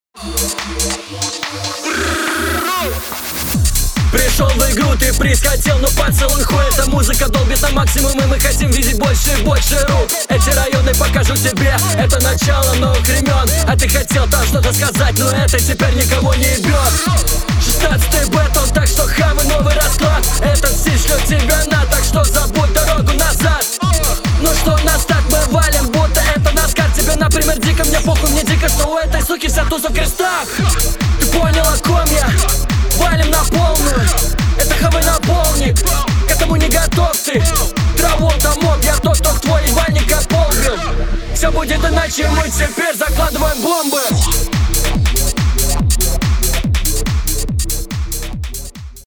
Нет, я постарался ощутить гармонию инструментала и читки, но не удалось, слишком энергичный бит и слишком спокойная (по его меркам) читка.
Очень лютый трек и стиль безумный.
Динамично, конечно, но настолько без мыслей и со всеми штампами танцулек в том числе с "бомбами" и "поднятыми руками"